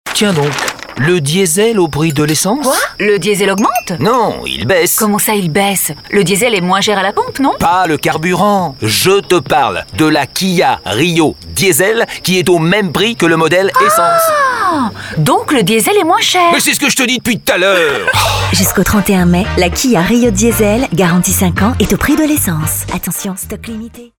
Un timbre de voix allant du médium au grave, Une voix élégante et chaleureuse, avec une large palette de styles d'interprétation et de tons.
Voix off dynamique et souriante dans cette bande annonce cinéma diffusée sur RMC à l’occasion de la sortie du film Yo Kai Watch.
Voix off pour vos bandes annonces cinéma